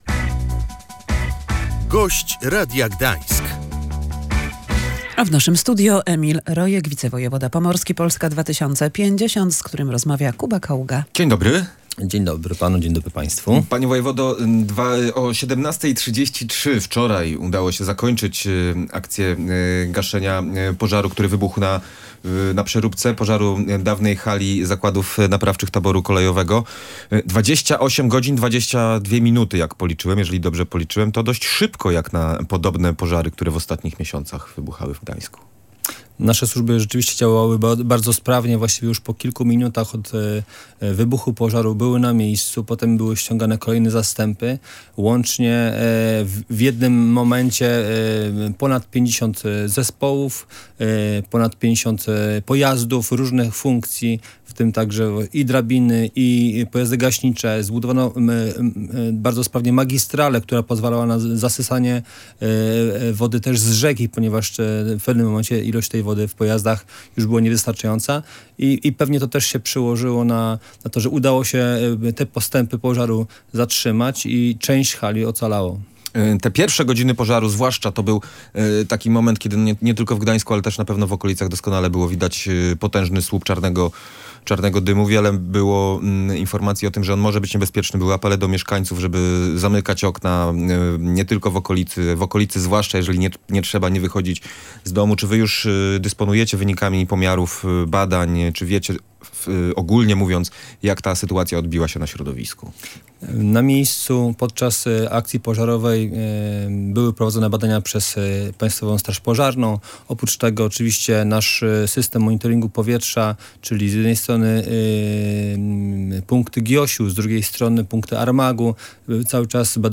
Na antenie rozmawiano także o tym, czy gdańszczanie powinni obawiać się związanych z pożarem zanieczyszczeń powietrza oraz wody.